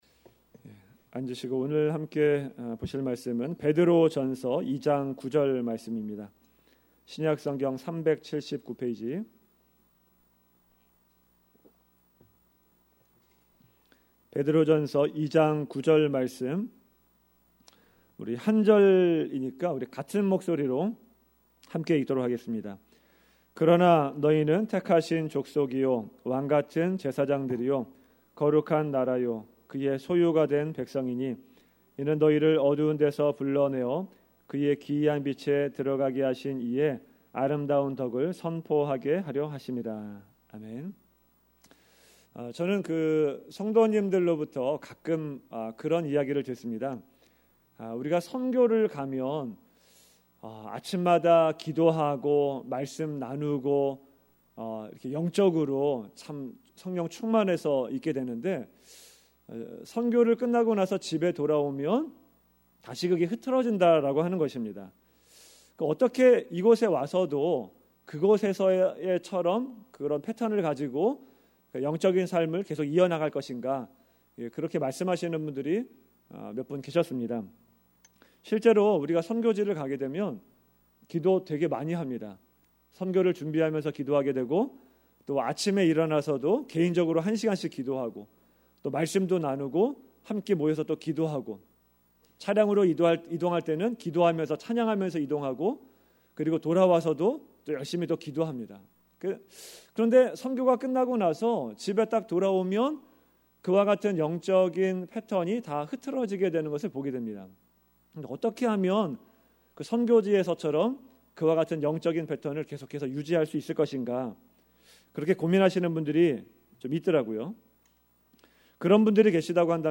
Categories: 2019년 금요설교 , Uncategorized